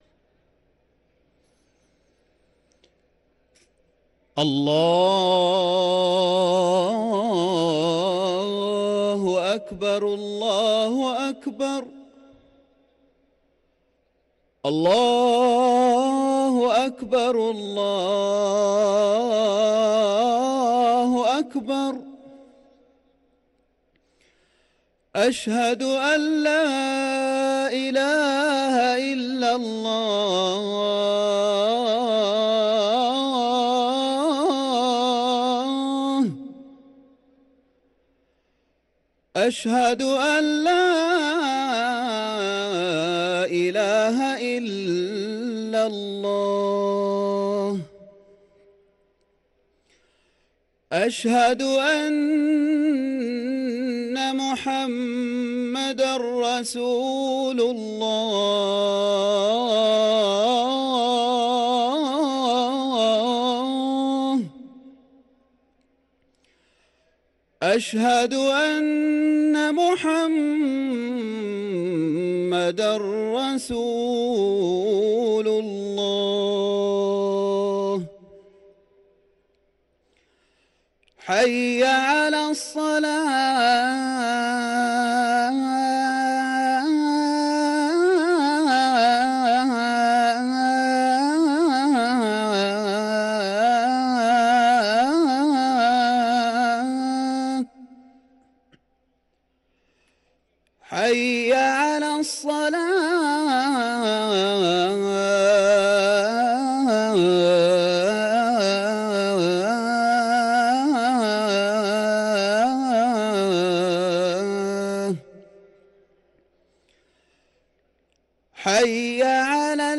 أذان العصر